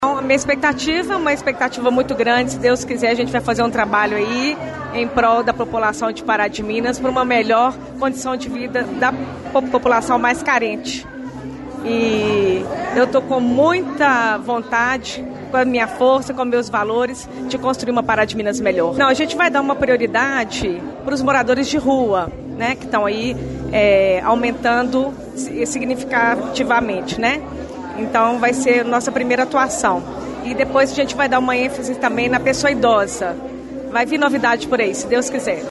Assumindo Assistência Social, Cláudia Assunção Faria, a Claudinha Tata, também destacou os principais objetivos da secretaria nos primeiros dias da legislatura 2025/2028: